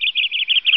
Add canary sounds
sounds_canary_01.ogg